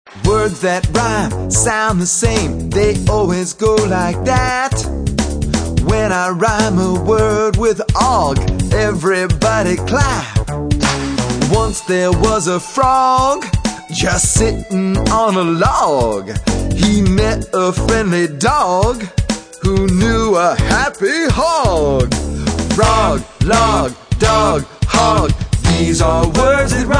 Chants, Cheers, Raps & Poetry Song Lyrics